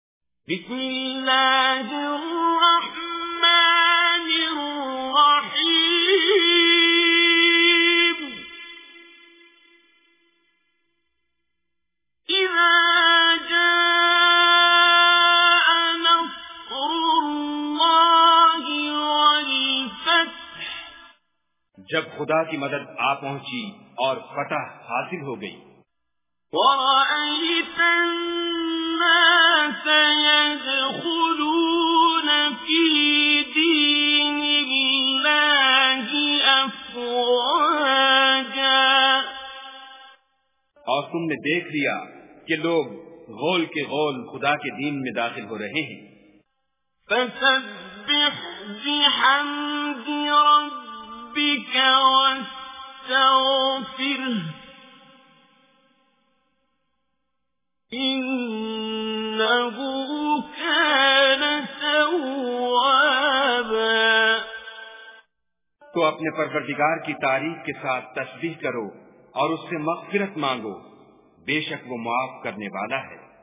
Surah An Nasr Recitation with Urdu Translation
surah-nasr.mp3